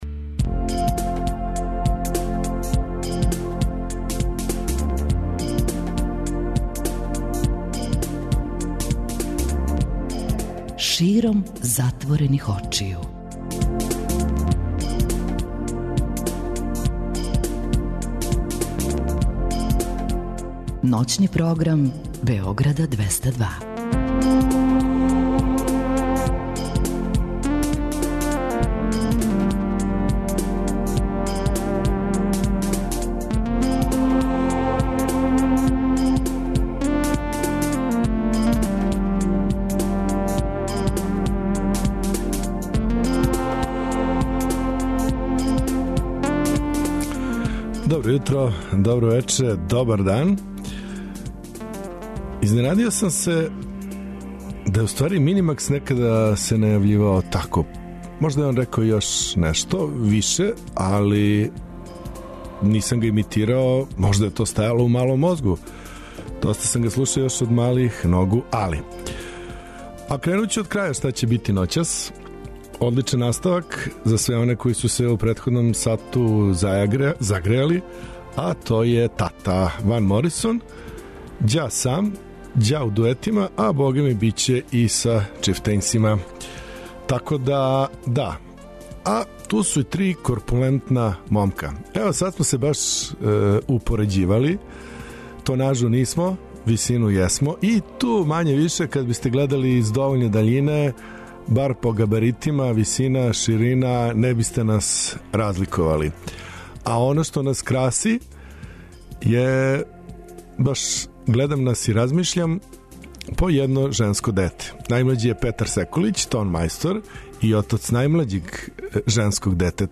музика 60-тих и 70-тих